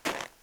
snowFootstep05.wav